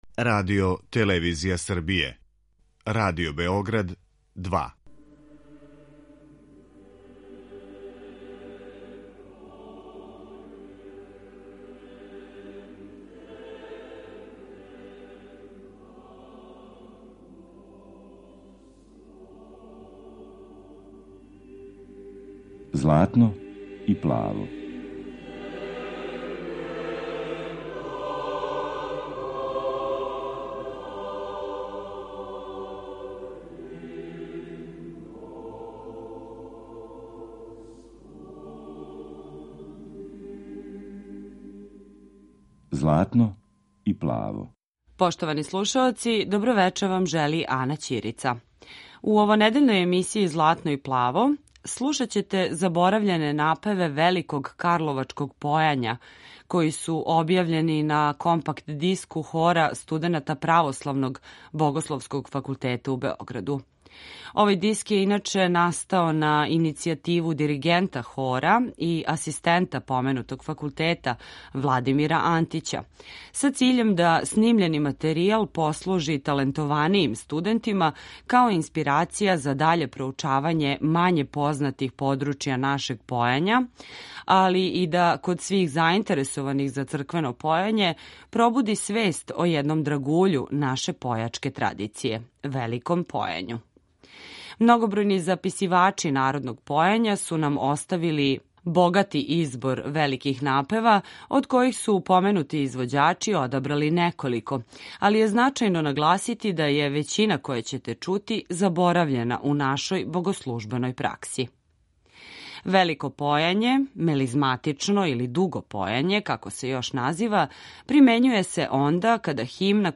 Заборављени напеви у извођењу Хора студената Православног богословског факултета у Београду
Велико карловачко појање